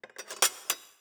SFX_Cooking_Knife_PutDown_01_Reverb.wav